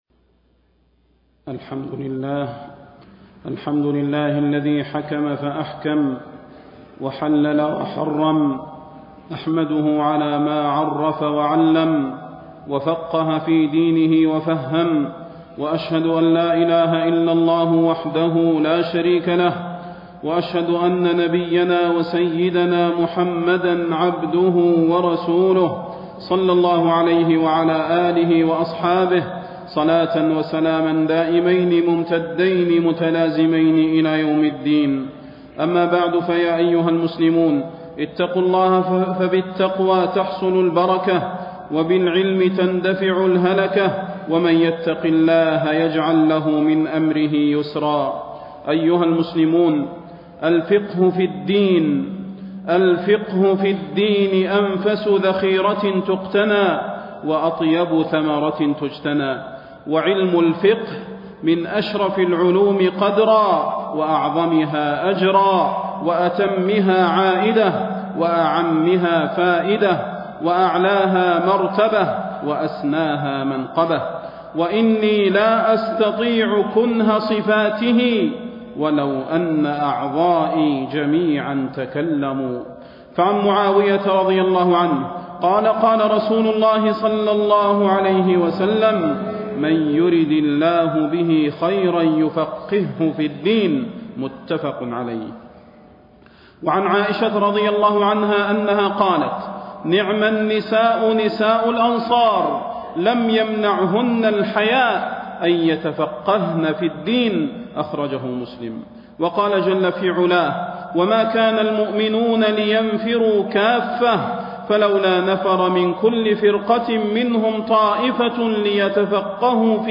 فضيلة الشيخ د. صلاح بن محمد البدير
تاريخ النشر ١٩ ذو القعدة ١٤٣٣ هـ المكان: المسجد النبوي الشيخ: فضيلة الشيخ د. صلاح بن محمد البدير فضيلة الشيخ د. صلاح بن محمد البدير تعلم قبل أن تعمل